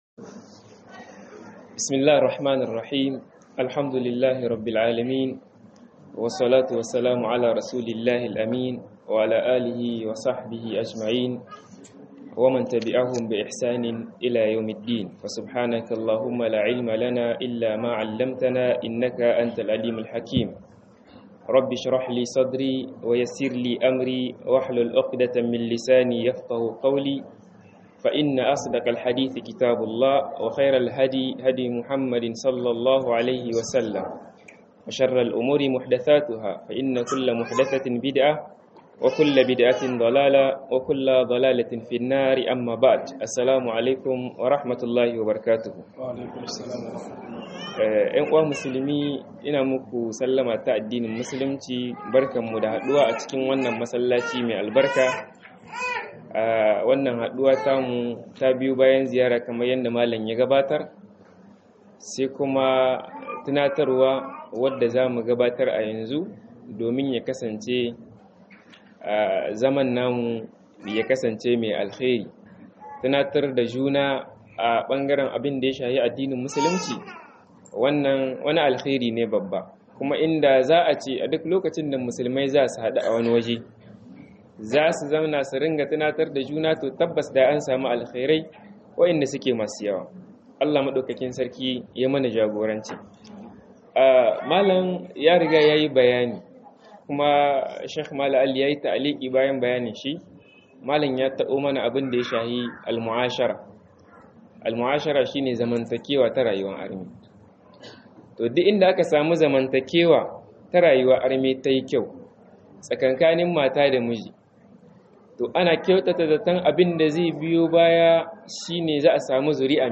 TARBIYAR YARA GARIN MATAMEY - MUHADARA